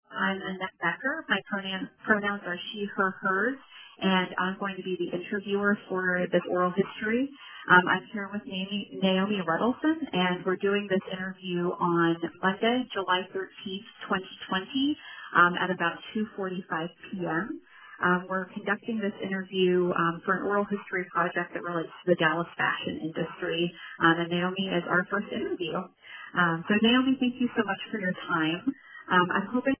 Interview introduction